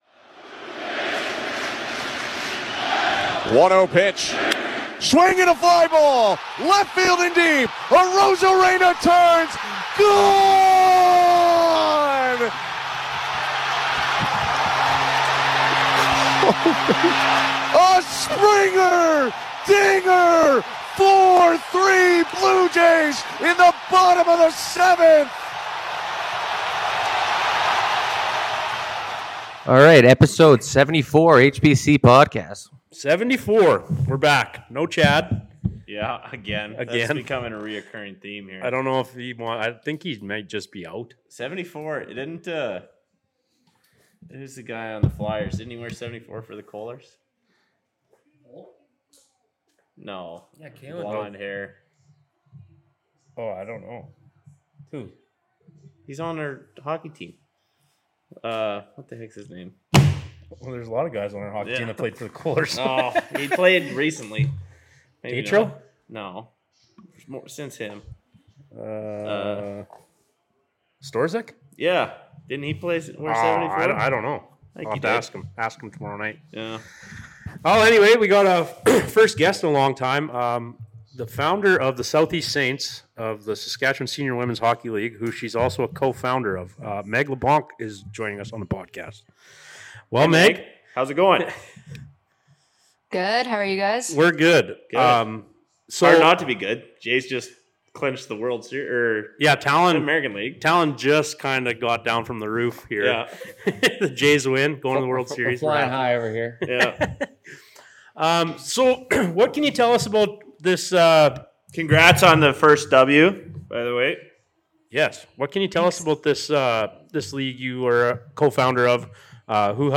A podcast about nothing from four Saskatchewan boys. Tune in for some light hearted banter fueled by the most reasonably priced beer from the liquor board store. The podcast features insight on senior sports in the province as well as a wide range of guests.